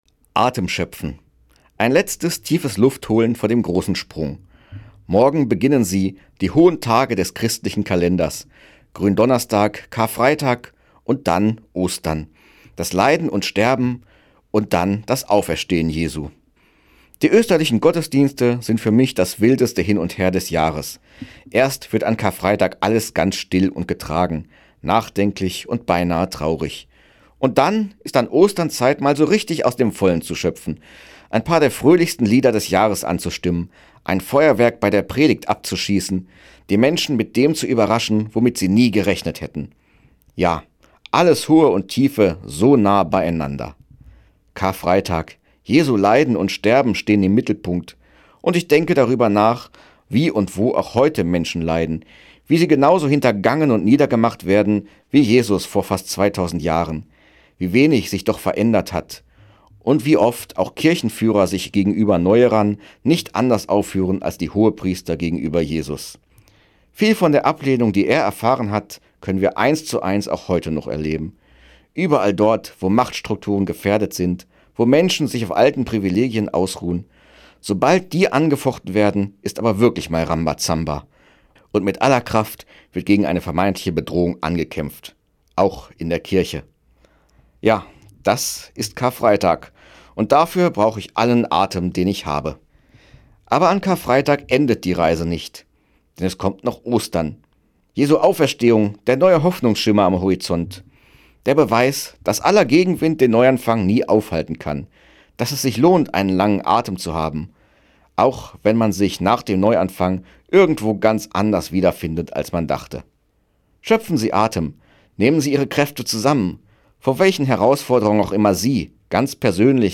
Weserbergland: Radioandacht vom 16. April 2025